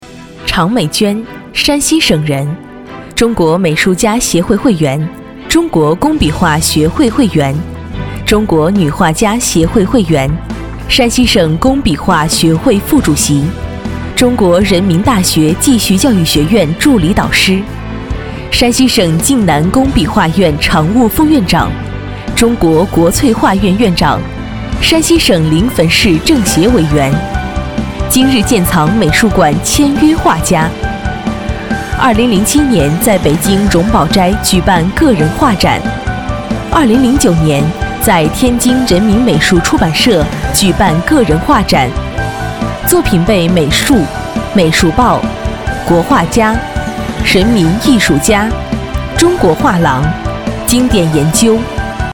年轻时尚 人物专题
年轻时尚女中音，甜美、活泼、大气沉稳。